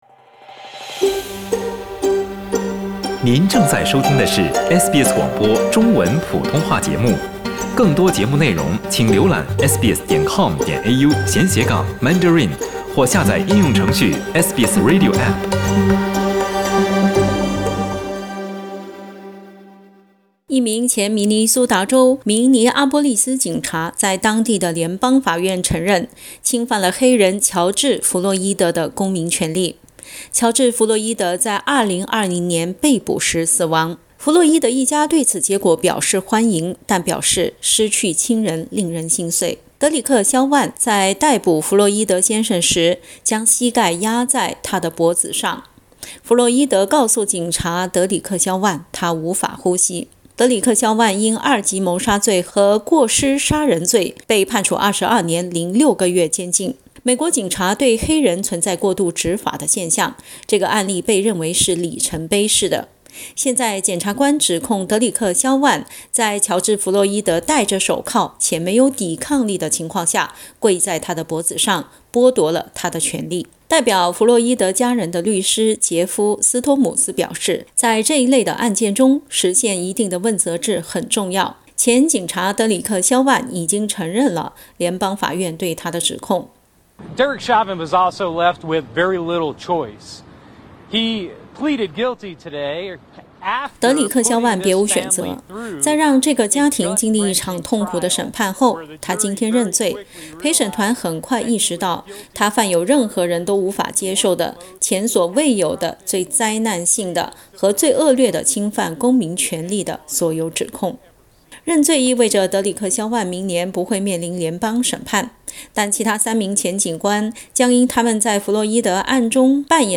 弗洛伊德的家人对此结果表示欢迎，但表示：失去亲人的痛苦从未减轻。 （点击图片收听报道）